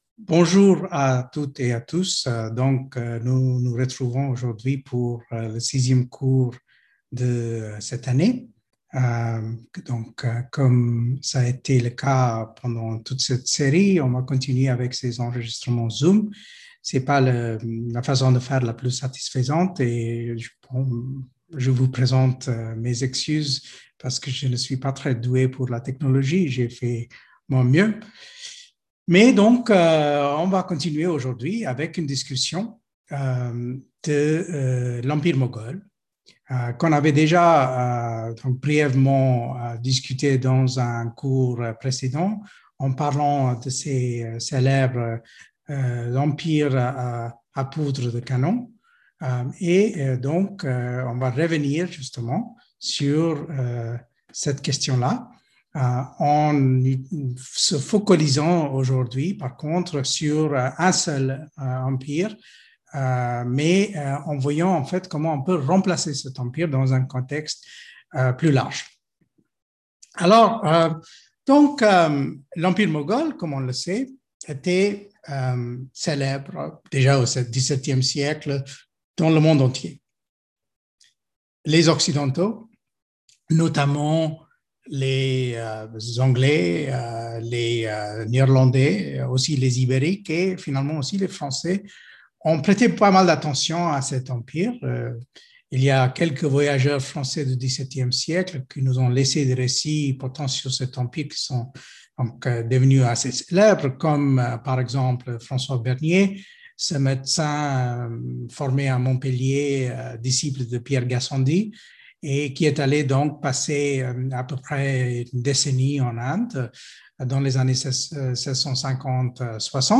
Speaker(s) Sanjay Subrahmanyam